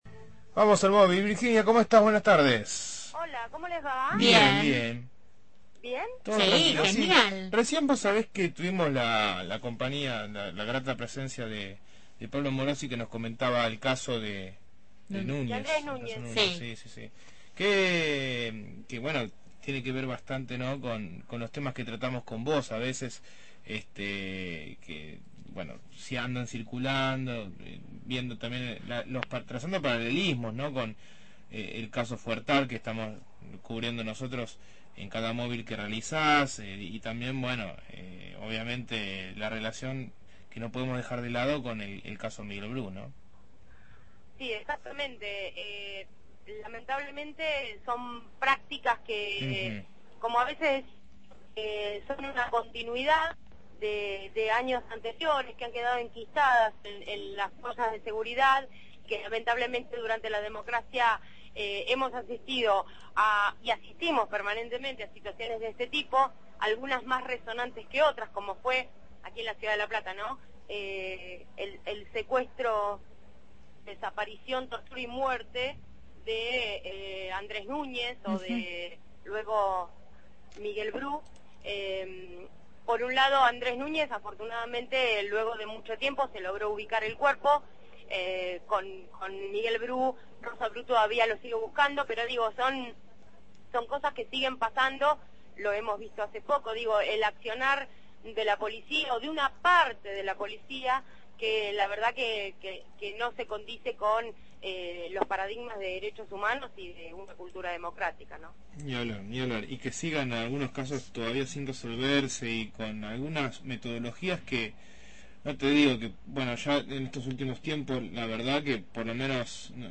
MÓVIL/ Inauguración de la Expo Universidad 2015 – Radio Universidad